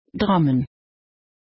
The Dutch word of the day is a verb and it is: “drammen“. Here’s how you pronounce it: